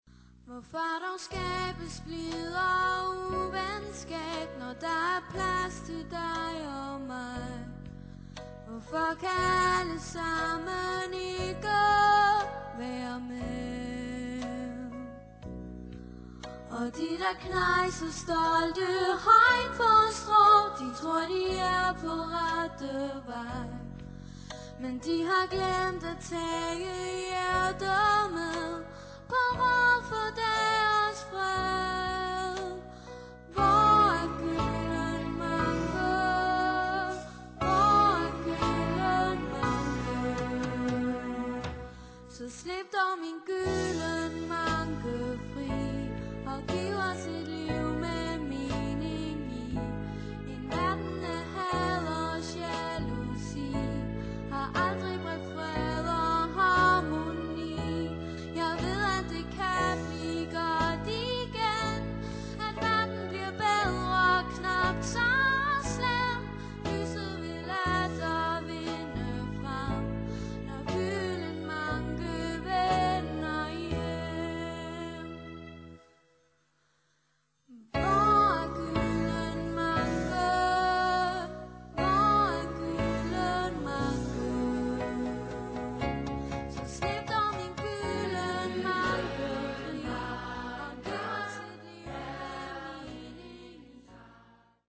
Derudover hører vi en stribe smukke og fængende ørehængere.